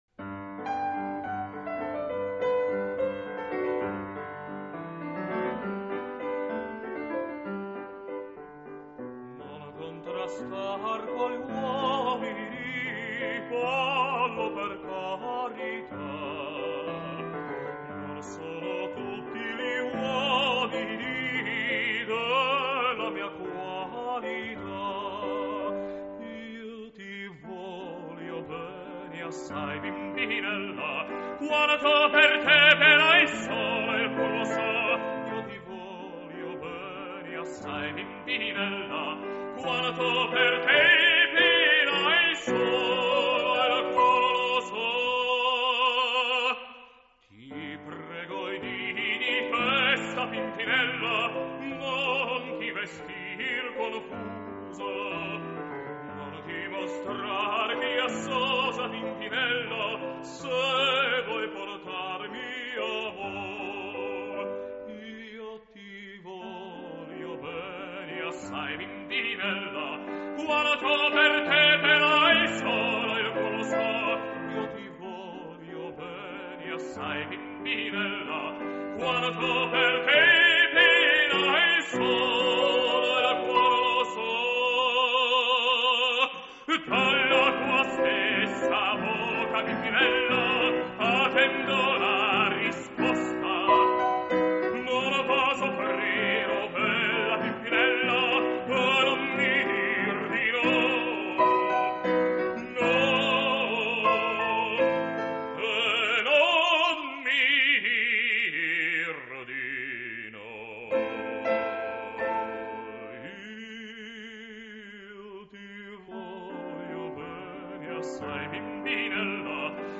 Романсы П.Чайковского и С.Рахманинова.
Партия фортепиано